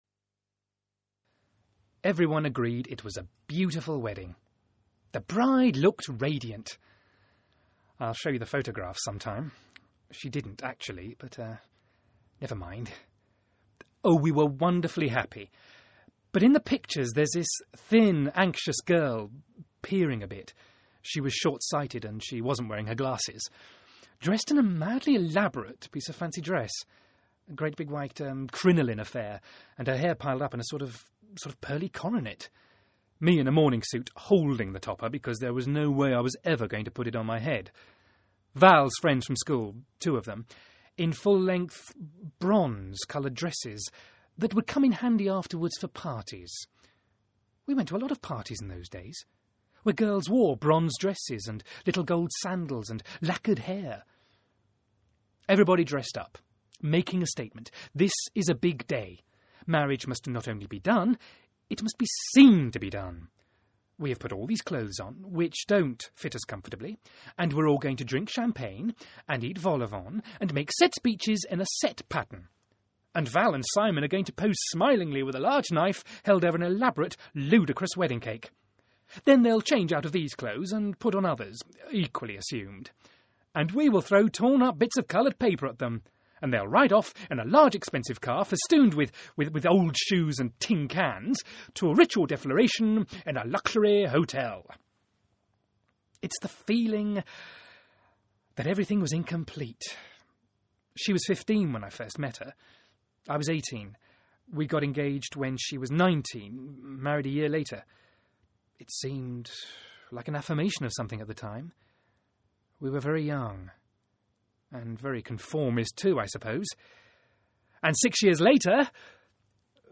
Audition Pieces